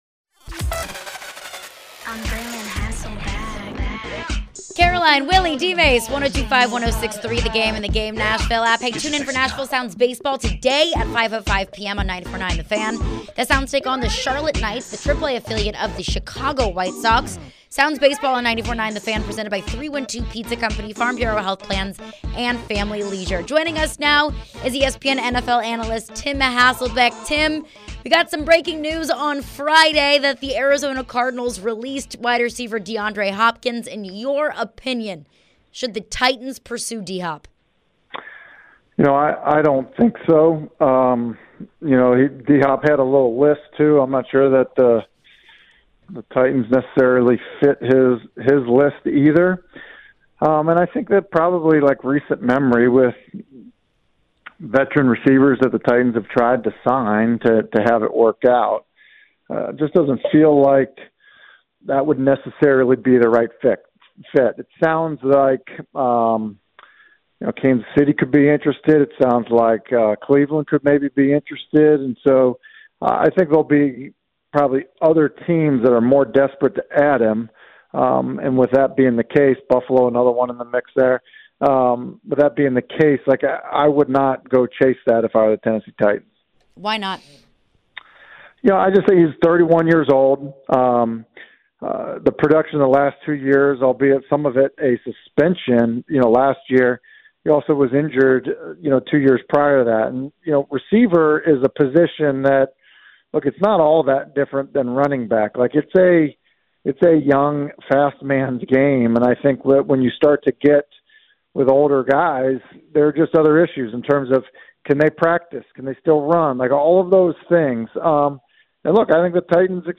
Tim Hasselbeck Interview (5-30-23)